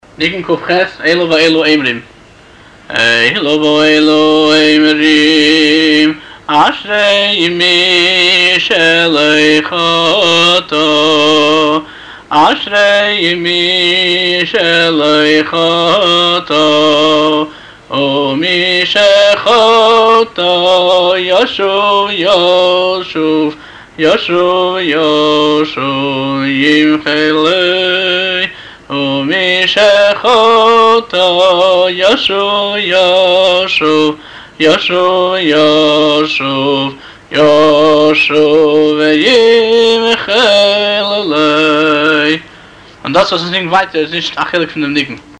באסופה נרשם: נעימת הניגון מתאימה לזכרון התשובה בתוך זמן השמחה.
התוועדות